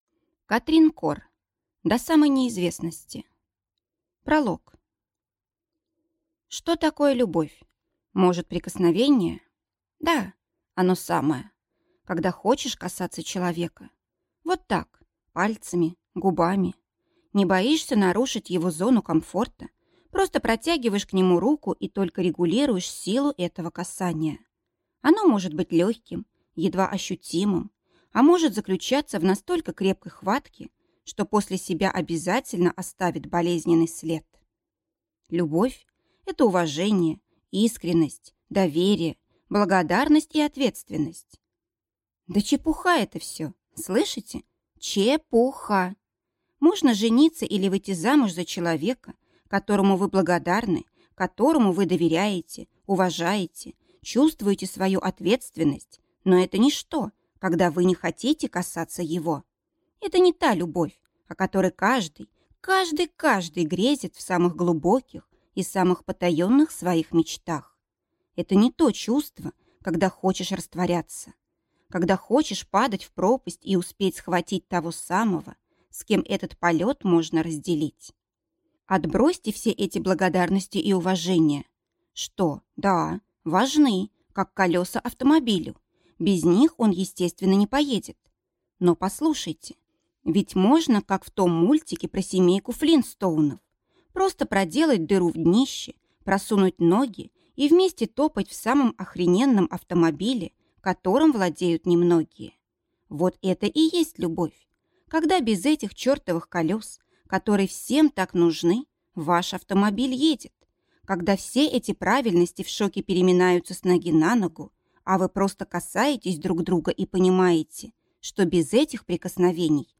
Аудиокнига До самой неизвестности | Библиотека аудиокниг